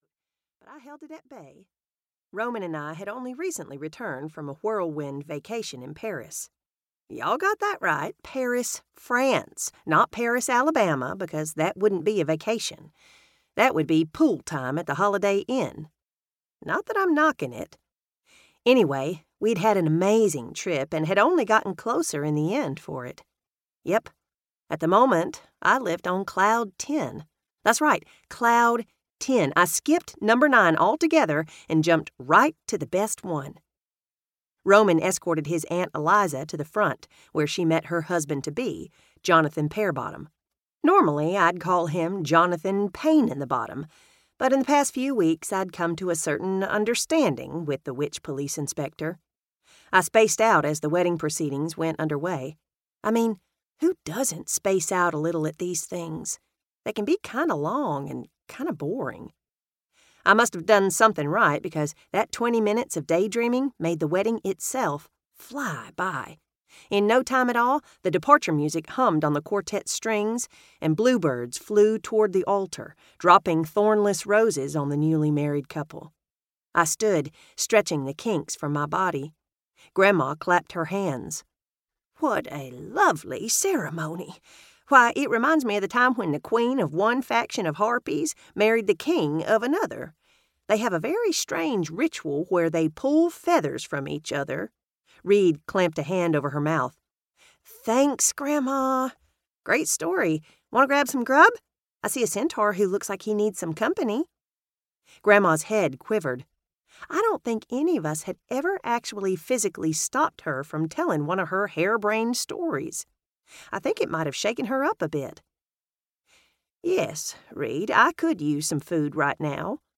Audio Books | Amy Boyles